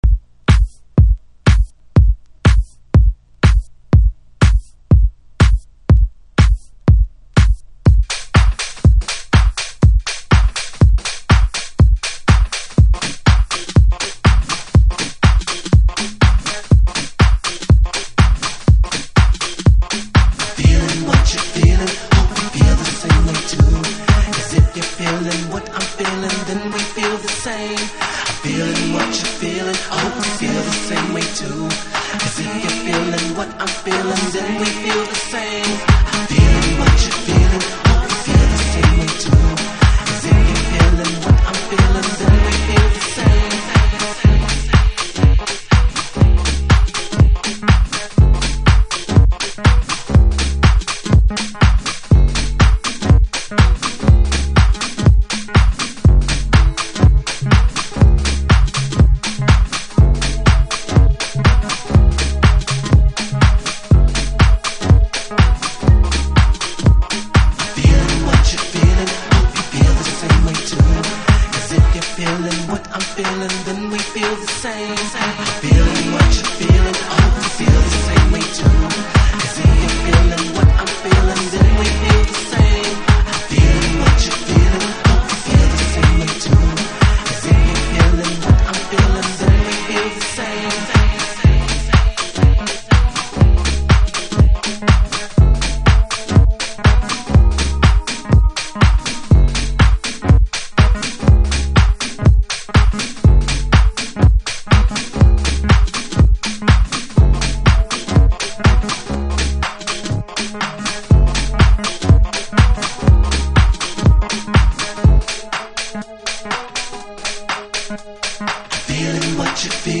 Detroit House / Techno
90'sマナーのディープとガラージの折衷感覚とゼロ年代以降のデトロイトのスモーキーな音像を併せ持ったTRAX。